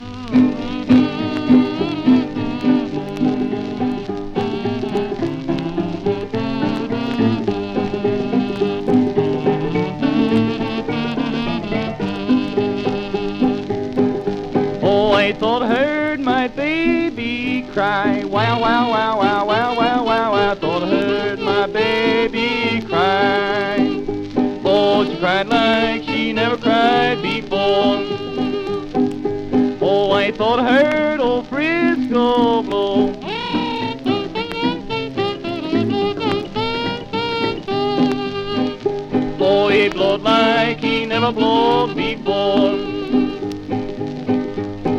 バンジョー
ギター＆カズー
Blues, Folk, World, & Country　Germany　12inchレコード　33rpm　Mono